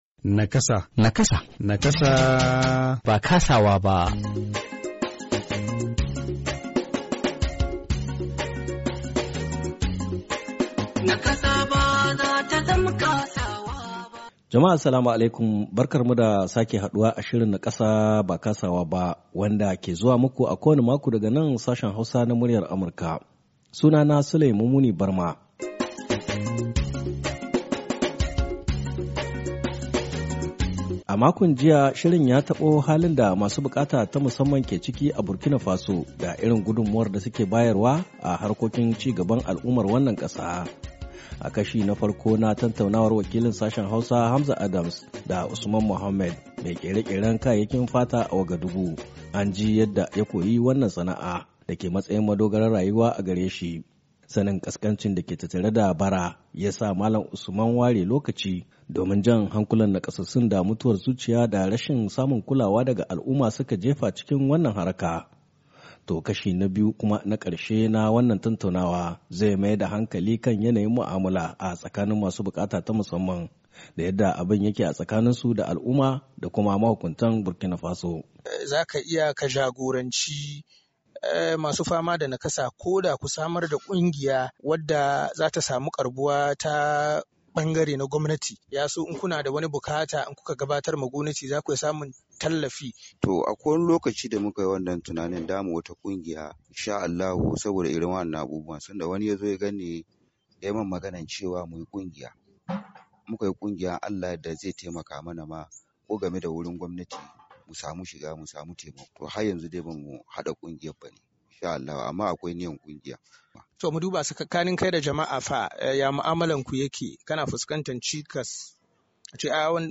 To kashi na biyu kuma na karshen wannan tantaunawa zai maida hankali kan yanayin mu’amula a tsakanin masu bukata ta musamman da yadda abin yake a tsakaninsu da al’umma da kuma mahukuntan Burkina Faso.